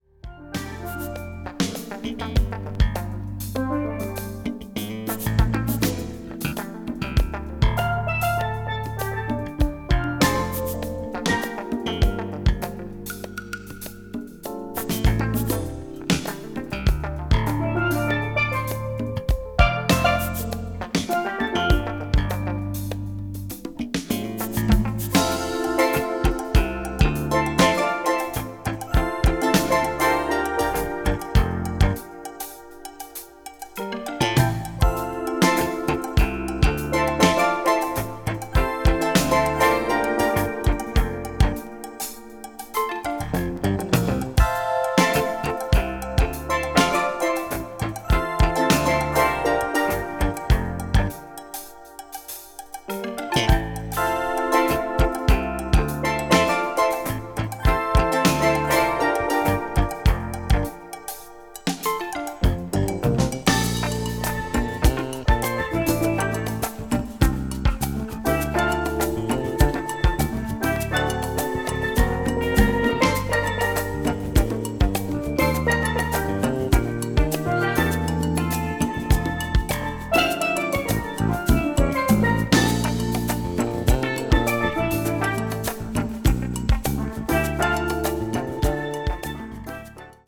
アーバン・バレアリックなフィーリングが気持ちイイA2/B3、涼しげでコンテンポラリーなB2がなかでも大推薦。
balearic   crossover   fusion   jazz groove   tropical